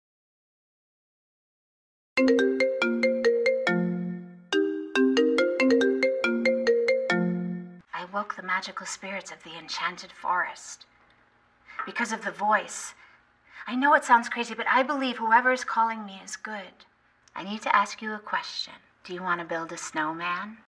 📌 Disclaimer: This is a fun fake call and not affiliated with Disney.